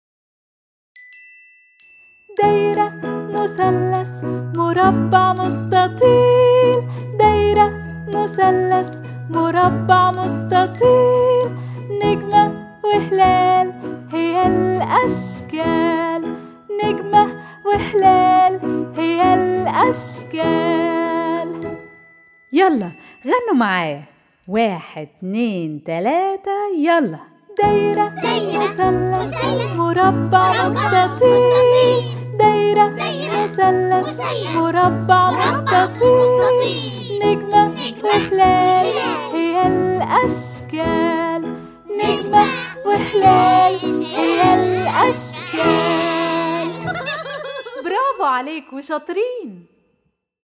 Their catchy rhythm and use of repetition gently boost their memory and vocabulary.